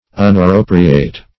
Unappropriate \Un`ap*pro"pri*ate\, a. [Pref. un- not +